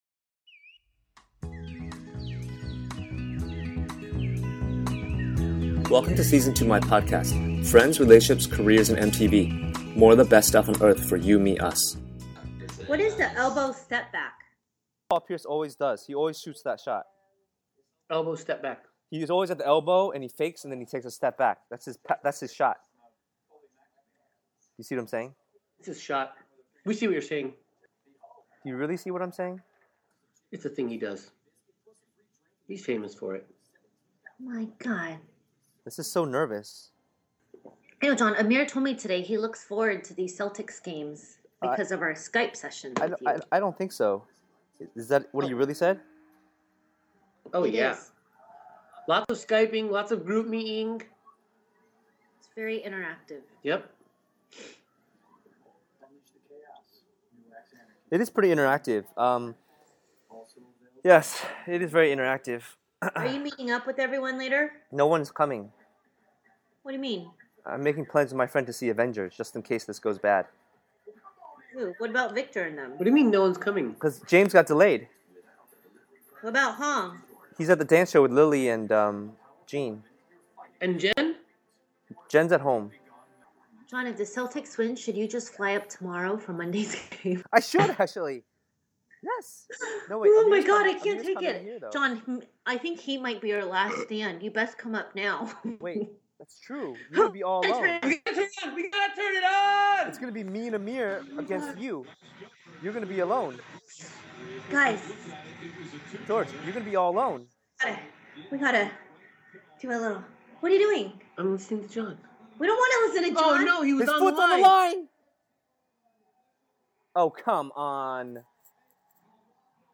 Excuse the screeching and screaming.